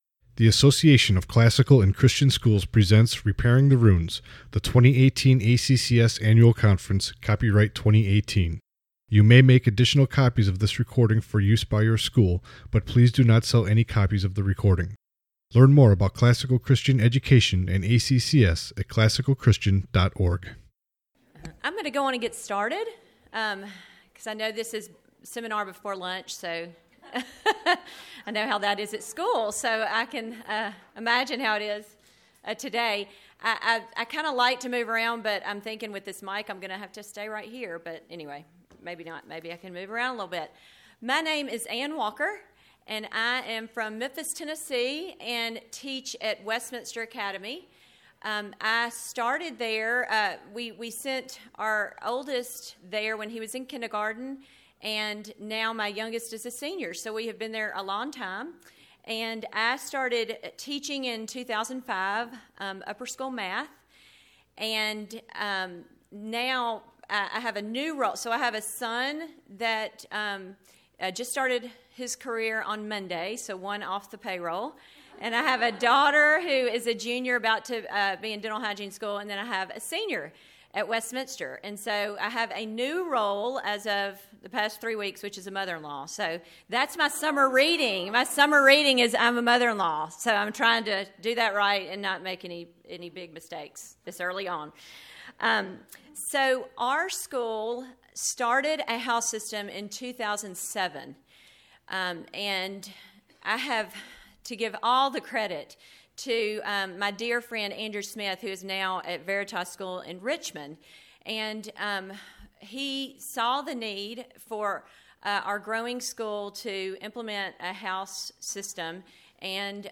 2018 Workshop Talk | 56:49 | 7-12